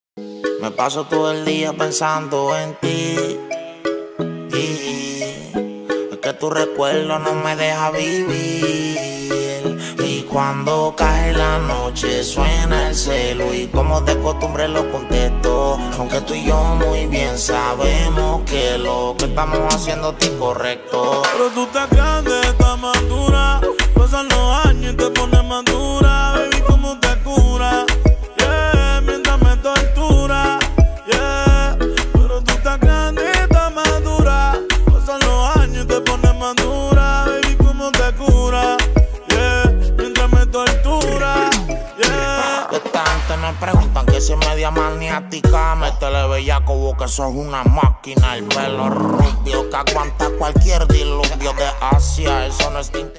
Reguetón